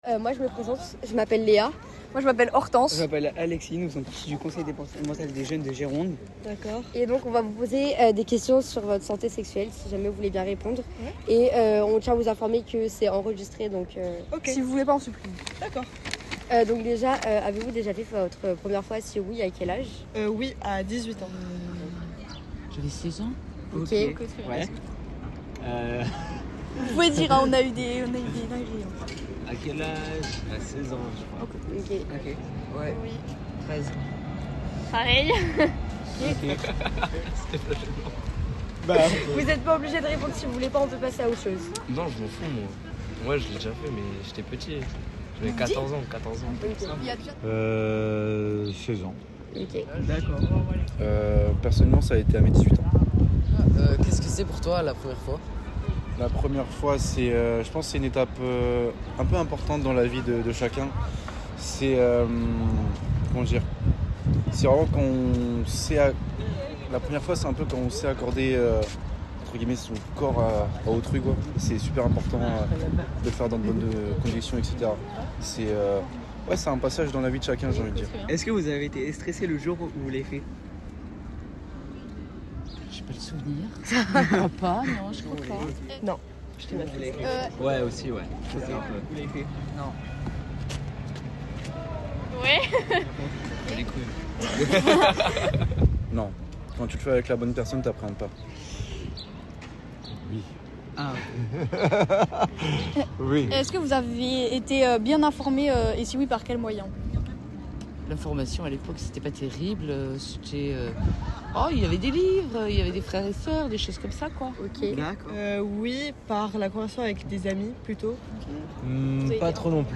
Dans une idée de suite de l’année de Grande Cause Départementale 2023 consacrée à la Jeunesse, la commission Concertation Politiques Jeunesse est allée à la rencontre de passants en réalisant deux micros-trottoirs : un premier sur la santé sexuelle et un second la santé mentale des jeunes.
Découvrez ci-dessous les micro-trottoirs réalisés par les jeunes élus.
Micro-trottoir premières fois